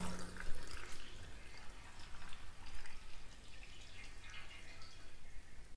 WS 氛围水排放
描述：像你一样站在下水道排水管，听水。我喜欢混响和通风。
Tag: 城市 环境 声音 野生